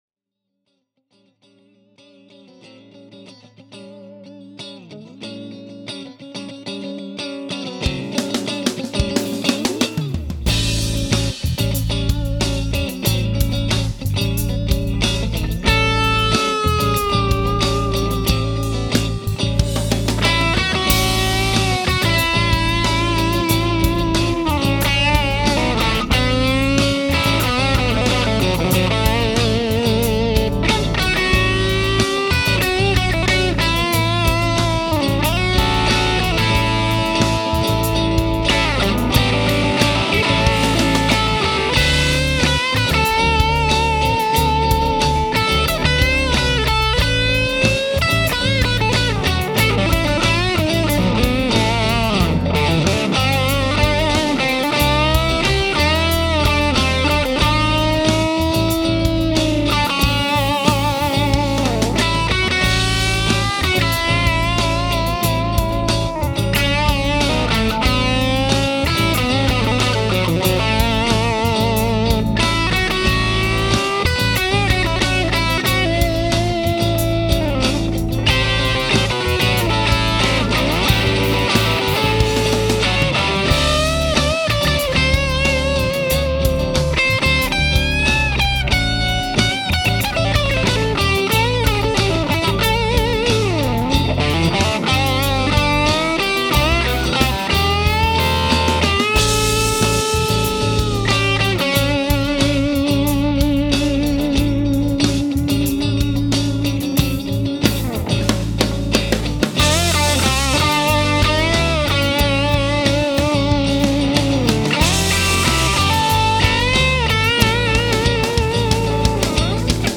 Excuse the obvious mistakes, the song’s not really in a finished state (can’t decide what guitar/amp combo I want to use).